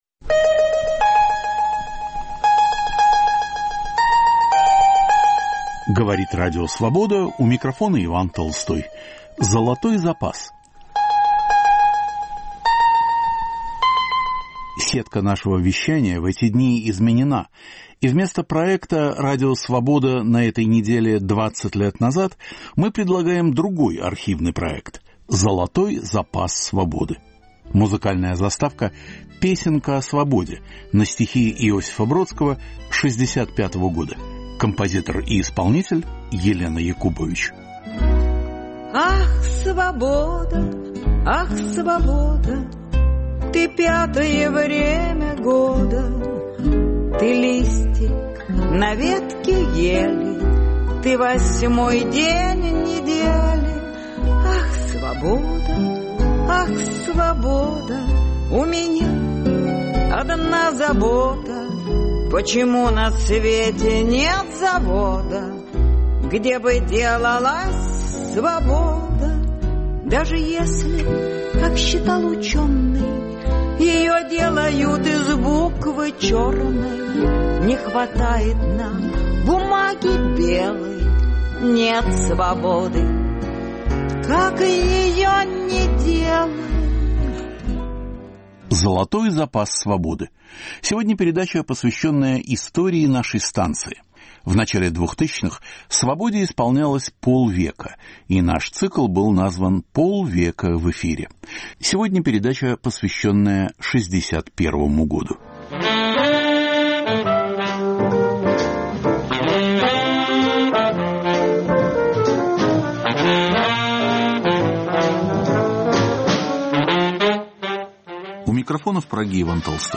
Цикл передач к 50-летию Радио Свобода. Из архива: полет Юрия Гагарина, 20-летие нападения Германии на СССР, беседа Нины Берберовой о Владиславе Ходасевиче, интервью с актрисой Лилей Кедровой, антисталинский съезд КПСС, Берлинская стена, музыкальный фестиваль в Сан-Ремо.